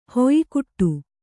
♪ hoyikuṭṭu